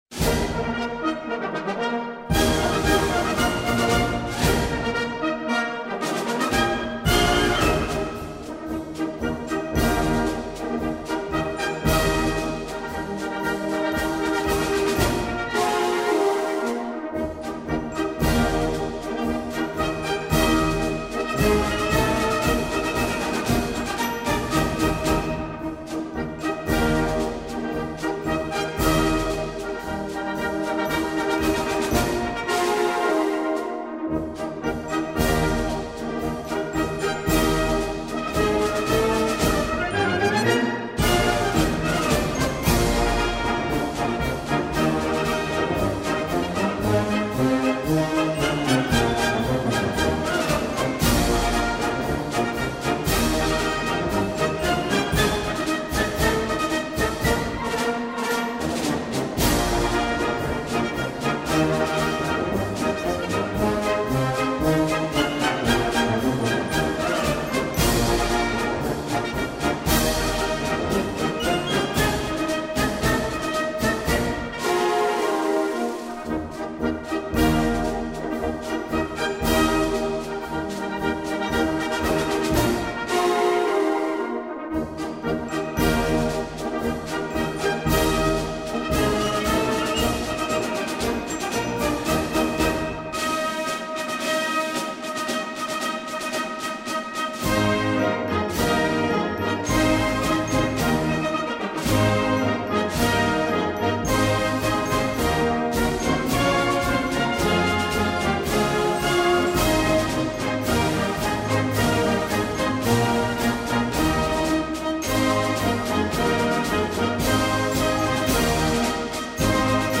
Оркестр штаба ЛВО, дир.
Парадный марш Слава Родине